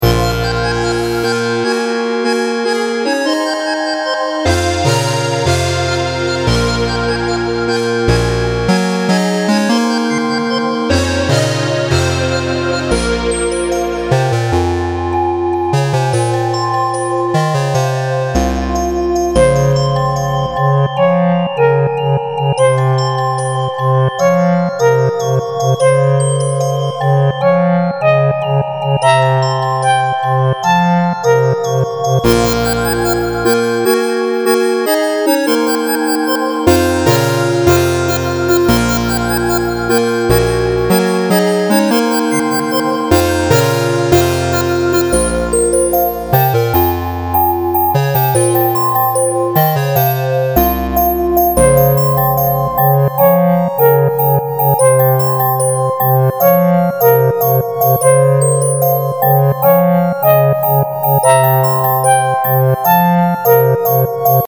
An echoey song for a cave, planetary level like Metroid, or intense conversation.